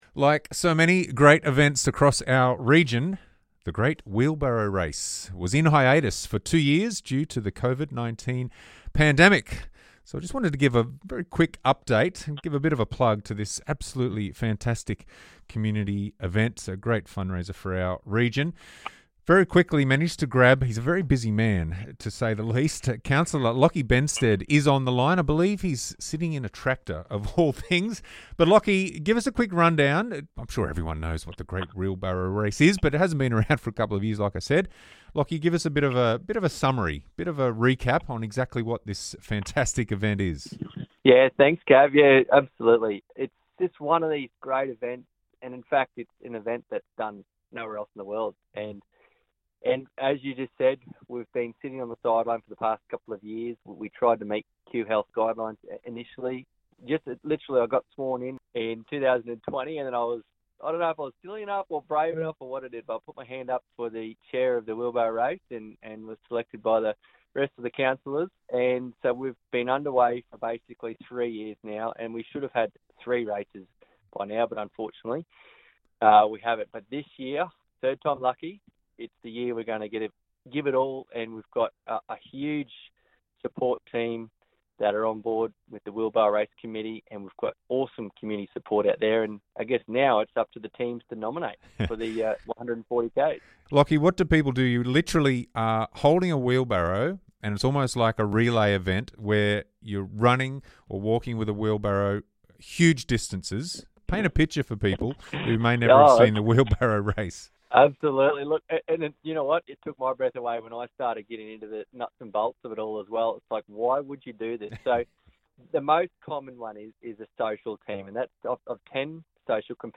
chats with Mareeba councillor Locky Benstead about the iconic Great Wheelbarrow Race